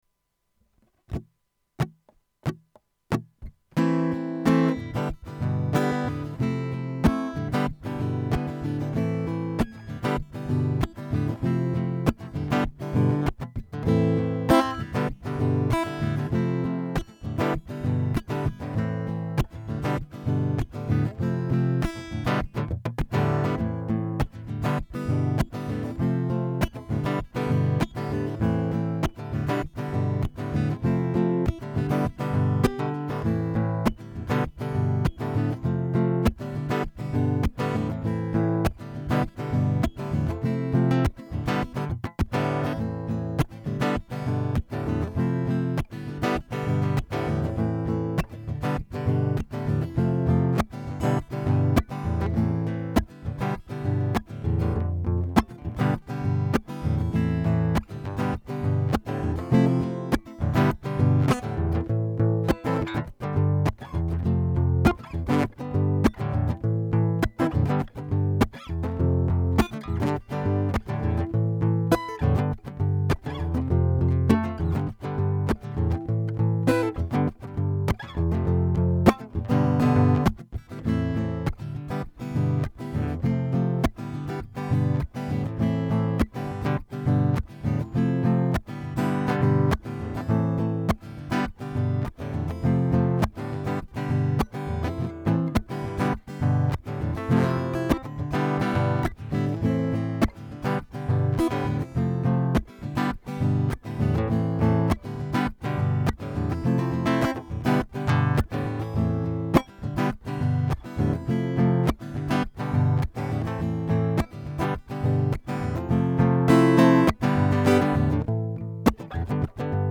Rocken zum Playback
Playback dazu in A-Moll (Am, G, F, G)